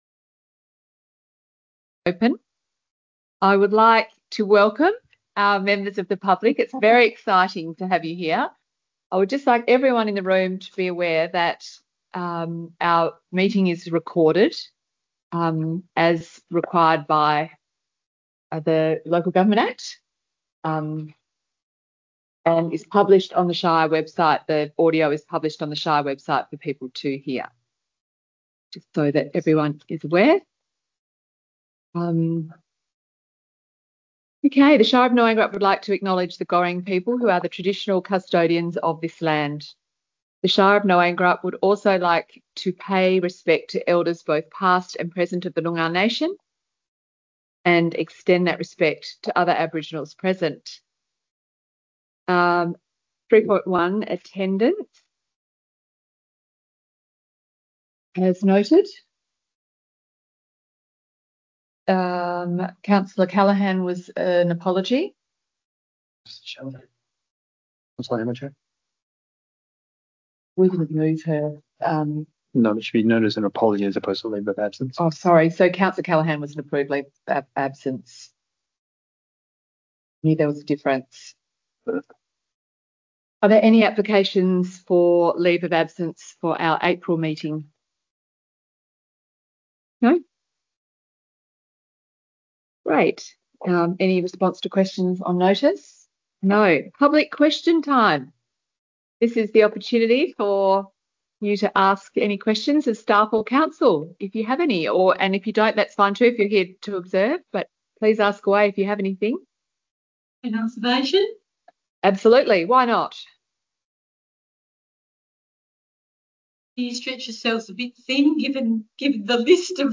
Ordinary Council Meeting 21 March 2025 » Shire of Gnowangerup